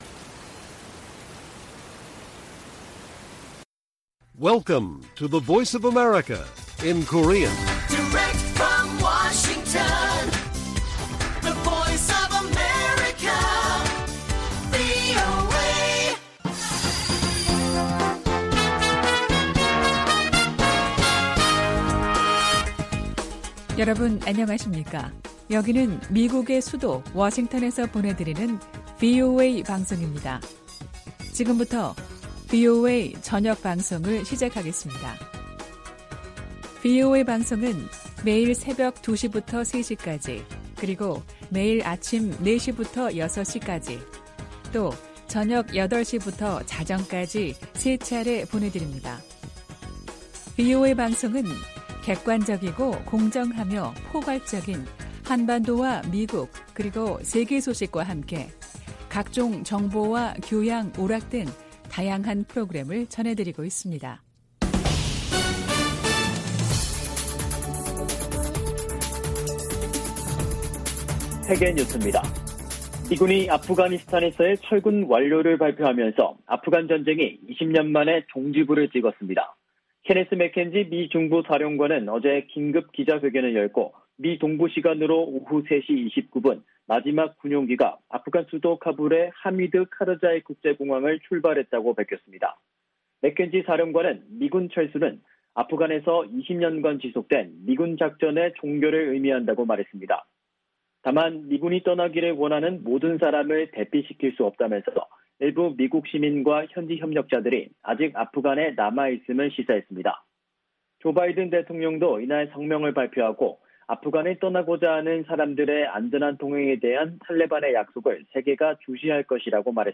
VOA 한국어 간판 뉴스 프로그램 '뉴스 투데이', 2021년 8월 31일 1부 방송입니다. 미국 정부는 북한 영변 핵 시설의 원자로 재가동 정황을 포착했다는 국제원자력기구 보고서에 대해 대화와 외교를 강조했습니다. 미국의 전직 핵 협상가들은 북한이 영변 원자로 재가동을 대미 압박과 협상의 지렛대로 이용할 수 있다고 분석했습니다. 미국 하원 군사위원회의 2022회계연도 국방수권법안에 4년 만에 처음으로 주한미군 감축을 제한하는 조항이 포함되지 않았습니다.